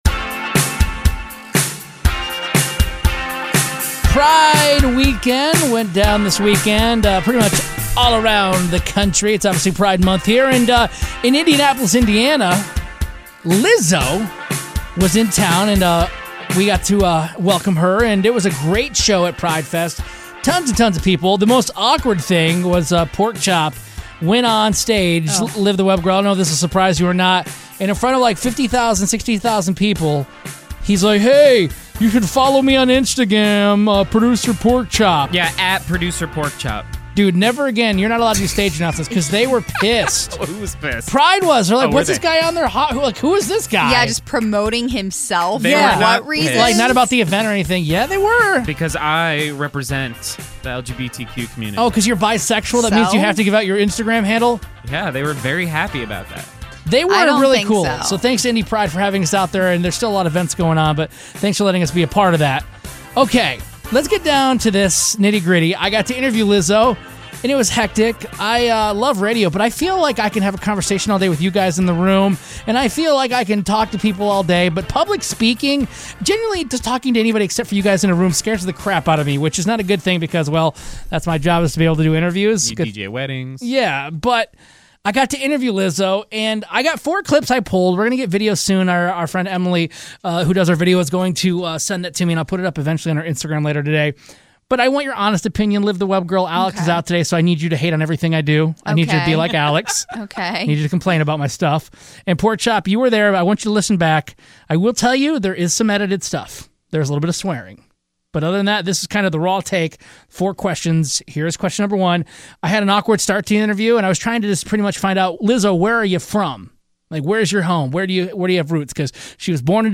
Lizzo Interview Recap